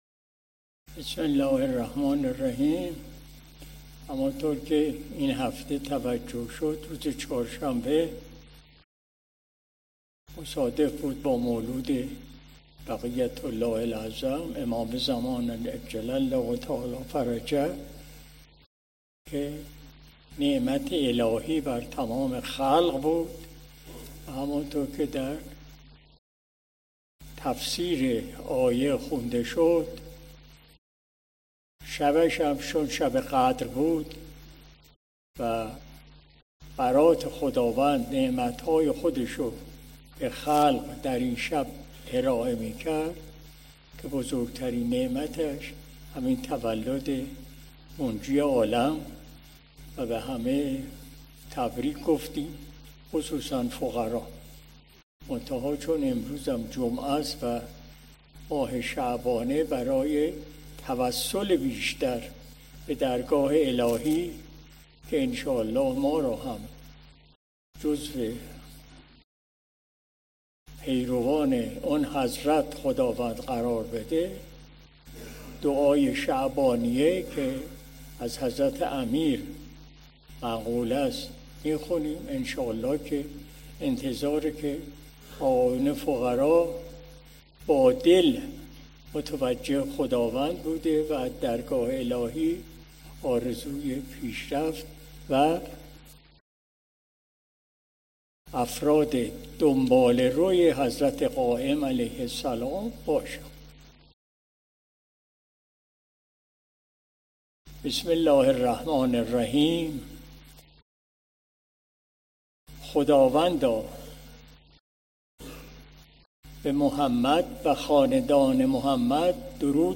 قرائت متن مناجات شعبانیه به مناسبت ۱۵ شعبان ولادت باسعادت حضرت ولی عصر (عج)